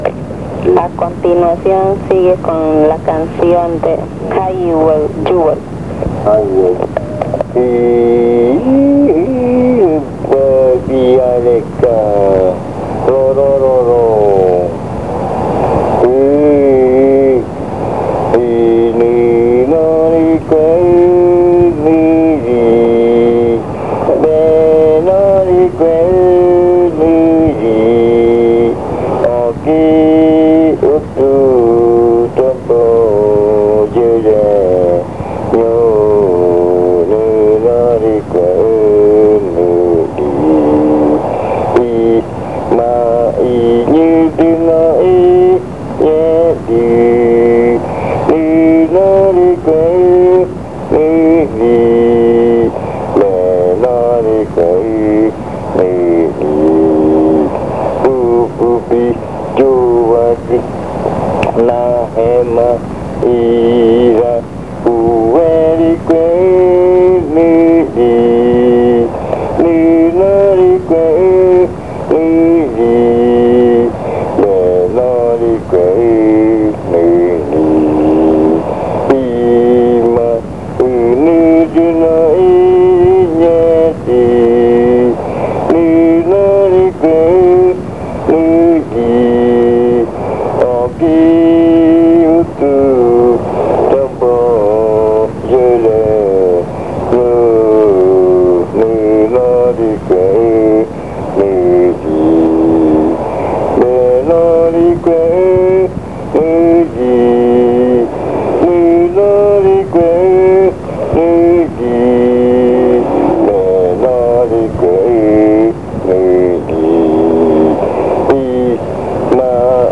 Leticia, Amazonas
Canción que se canta medianoche. Dice: ¿Donde voy a dormir?.
Chant that is sung midnight. It says: Where am I going to sleep?